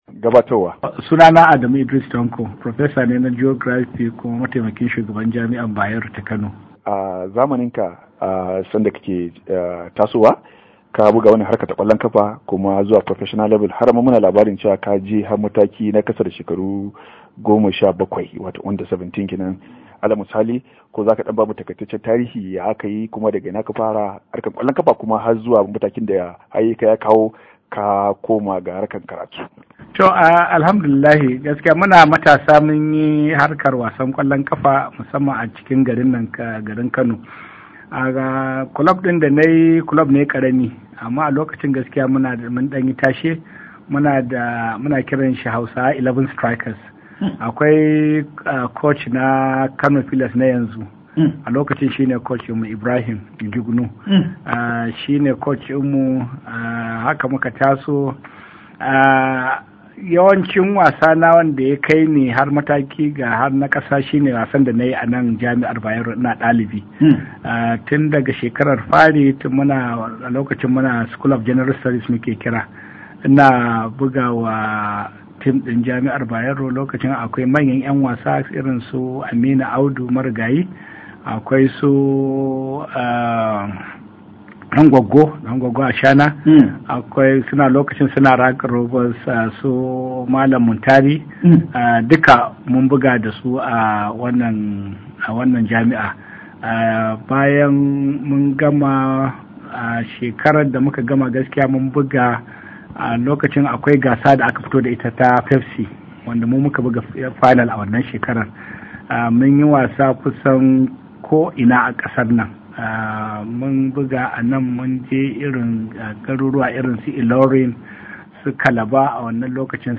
Ga cikakken hirar ta su.